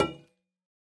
Minecraft Version Minecraft Version latest Latest Release | Latest Snapshot latest / assets / minecraft / sounds / block / copper_bulb / place3.ogg Compare With Compare With Latest Release | Latest Snapshot